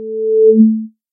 描述：正弦波440赫兹与正弦波220赫兹相混合